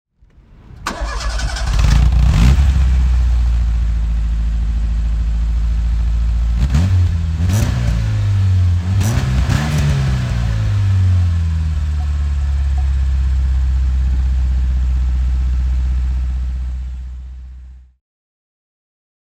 Bertone Cabrio Palinuro (1985) - Starting and idling
Bertone_Ritmo_Cabrio_1985.mp3